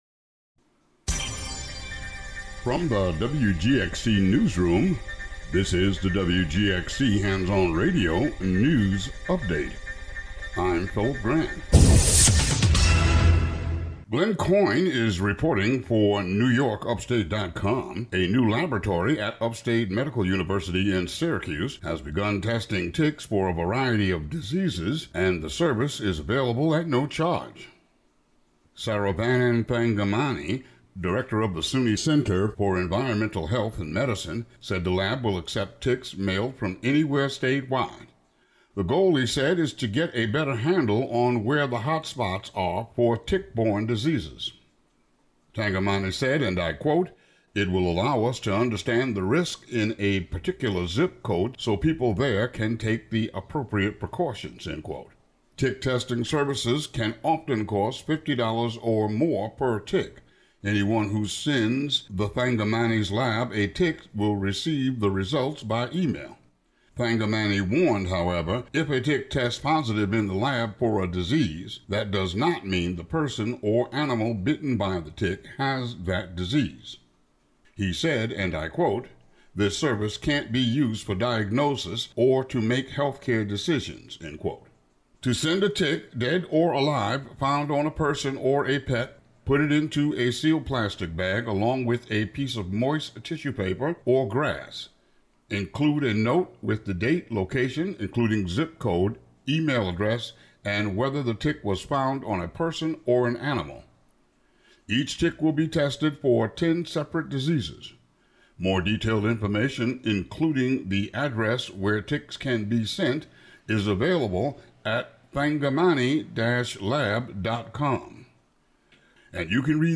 Local news update for WGXC.